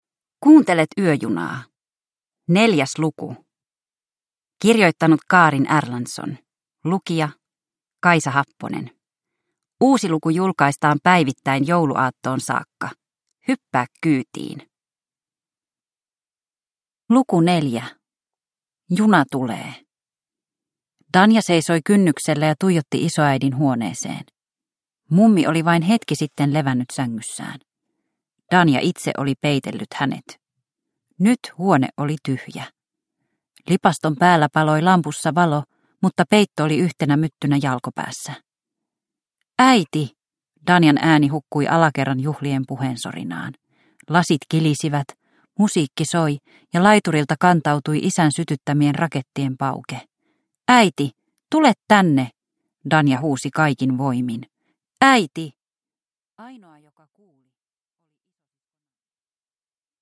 Yöjuna luku 4 – Ljudbok